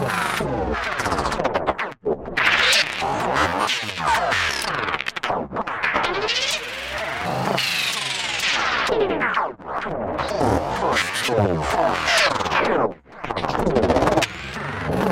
fractured texture robotic speech.ogg
Original creative-commons licensed sounds for DJ's and music producers, recorded with high quality studio microphones.